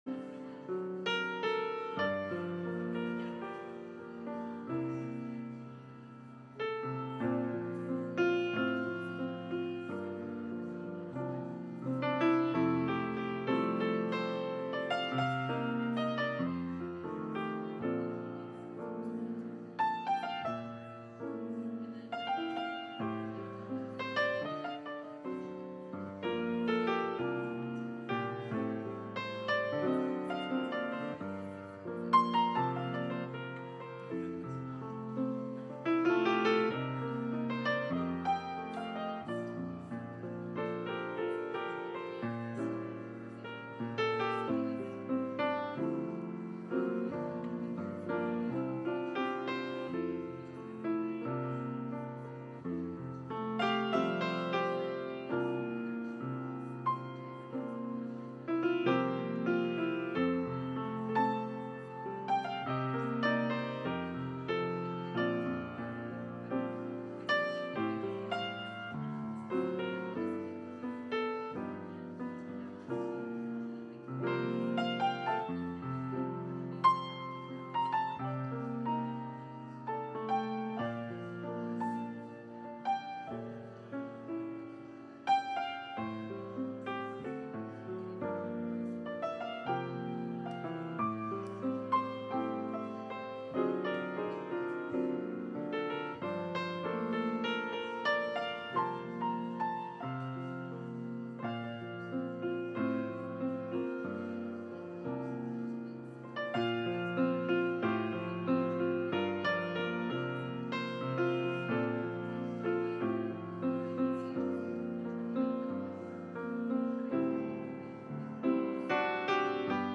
Sermons | Peninsula United Church